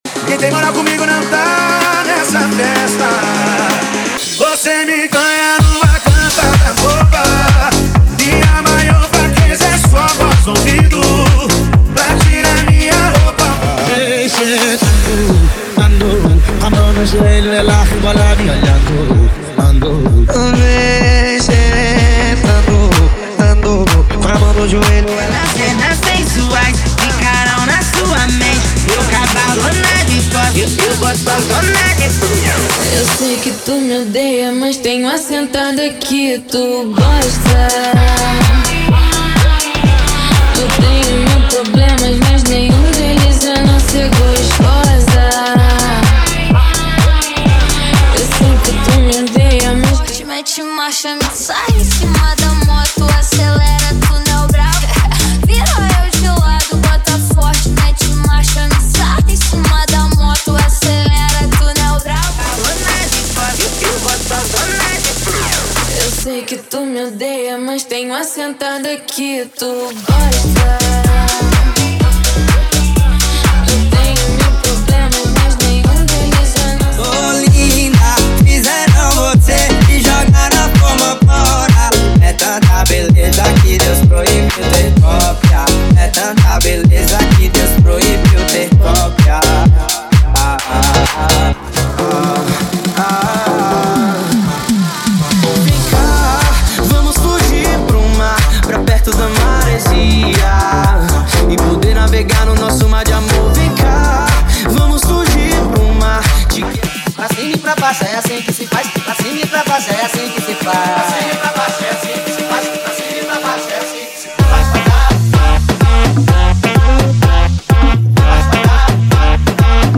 REMIX NACIONAL = 50 Músicas
Sem Vinhetas
Em Alta Qualidade